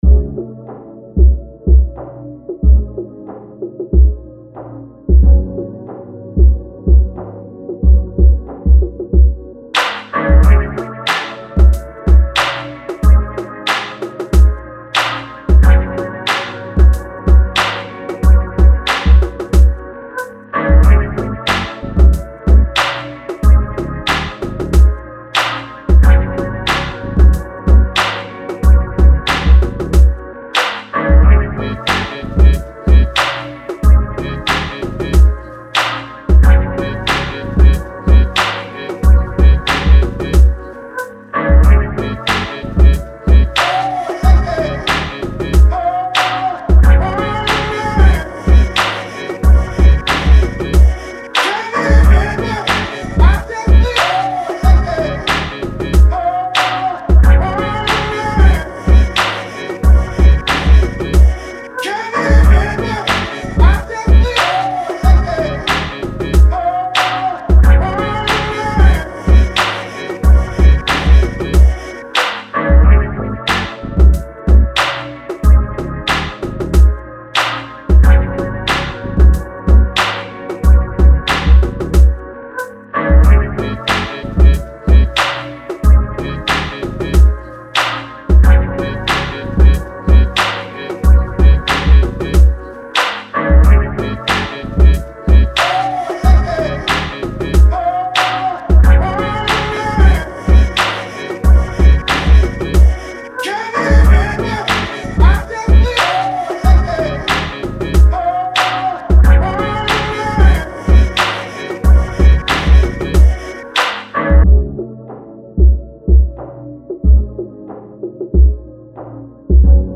I did one just now, didn’t use any outside elements.